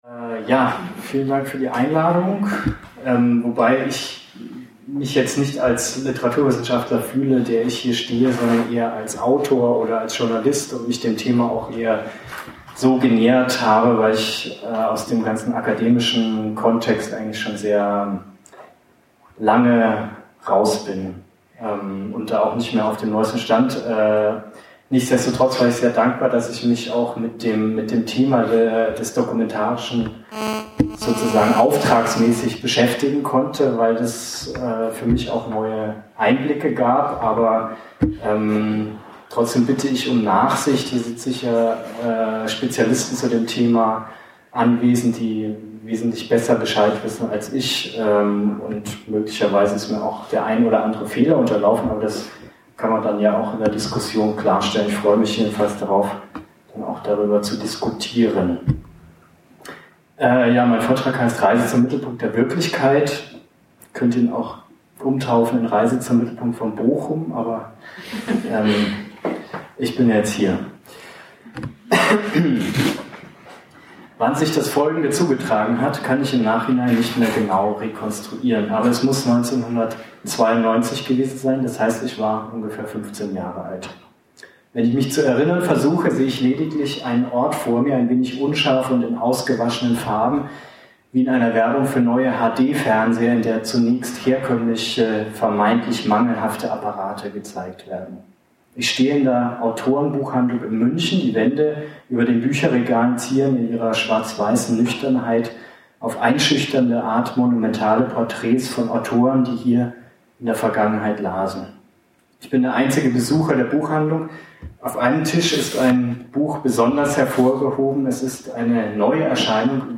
Warum sind wir heute süchtig nach Dokumenten und nach dem, was sich dahinter verbirgt und von dem keiner weiß, was genau das eigentlich sein soll: die Wirklichkeit? Eine Reise von der Fotografie bis You Now. (abweichender Veranstaltungsort: Blue Square | Raum 2/1 | Kortumstr. 90 | 44787 Bochum)